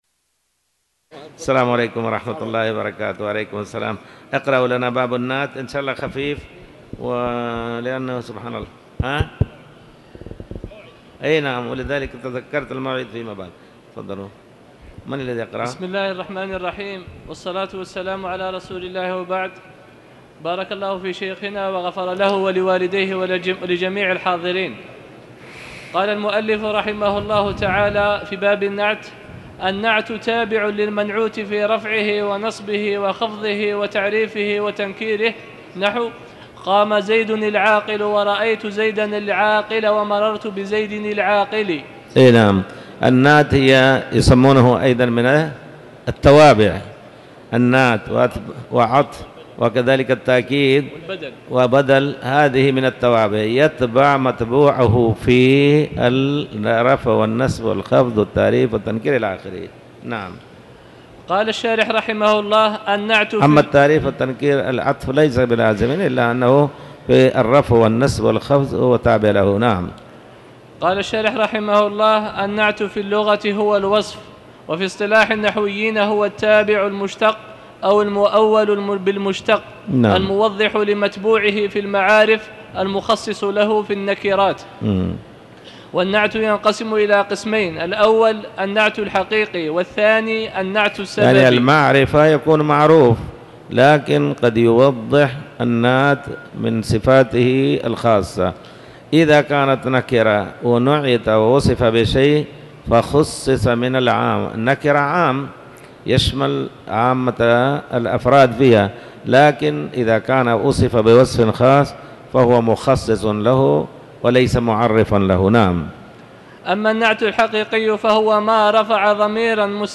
تاريخ النشر ٩ ربيع الأول ١٤٤٠ هـ المكان: المسجد الحرام الشيخ